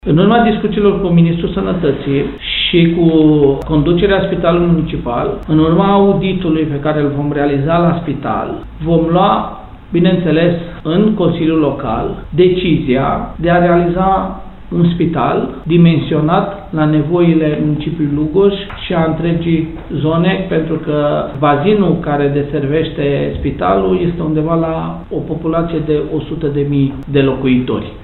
Finanțarea unui spital nou în municipiul Lugoj ar putea veni tot din fonduri nerambursabile, printr-o linie a Băncii Europene de Investiții, spune primarul Călin Dobra.